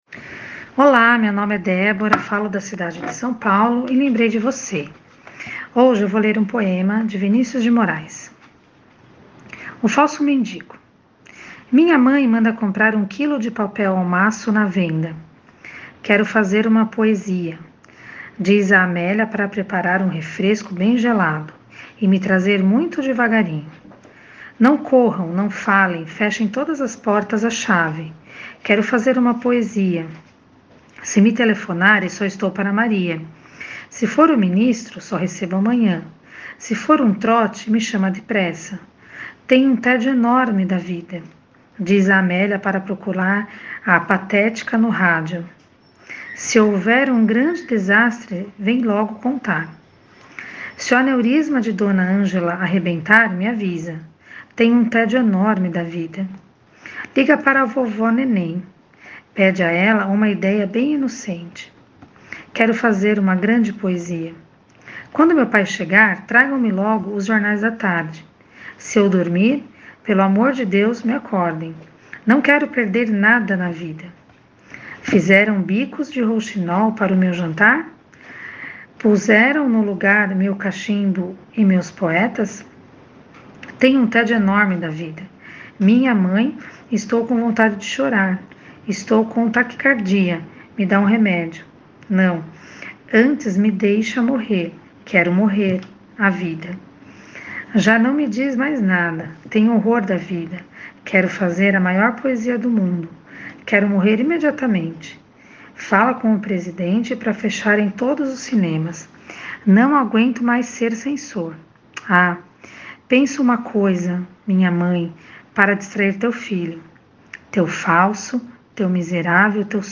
Poesia Português